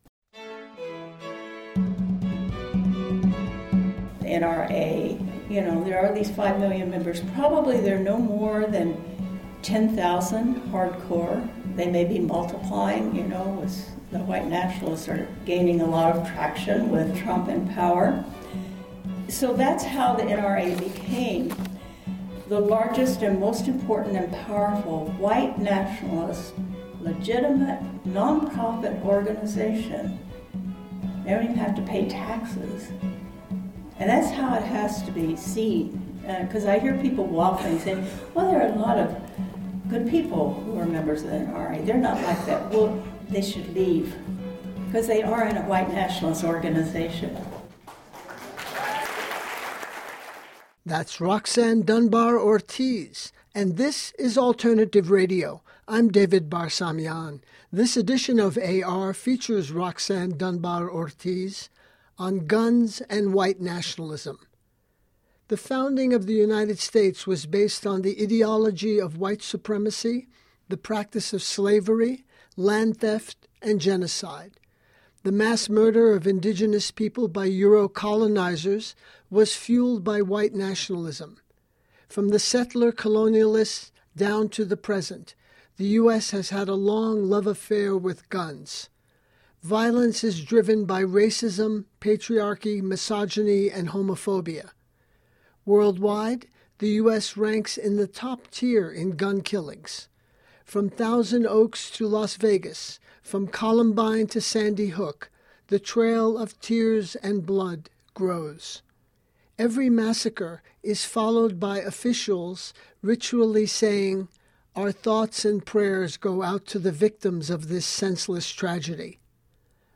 File Information Listen (h:mm:ss) 0:57:00 Roxanne Dunbar-Ortíz Guns & White Nationalism Download (2) ORTR006-GunsWhiteNationalism.mp3 34,308k 80kbps Mono Comments: Recorded in Chicago, IL on July 5, 2018 Listen All